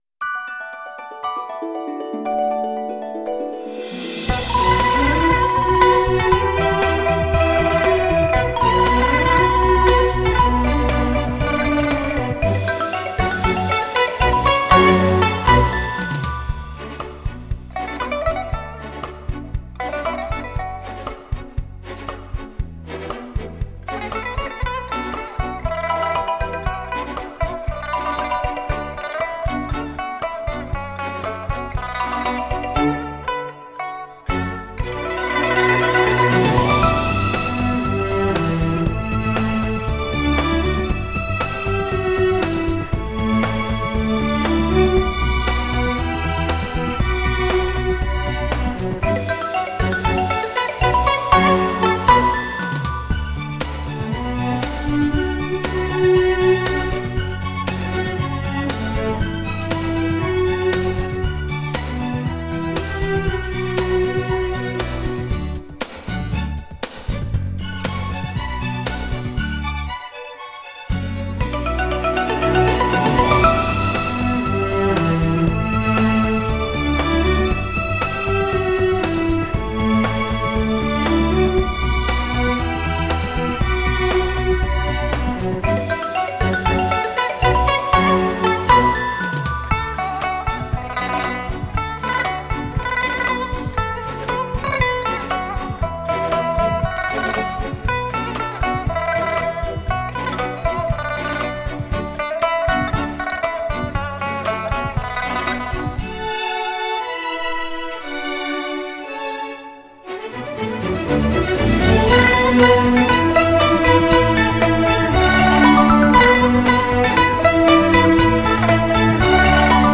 儿童歌曲
D调伴奏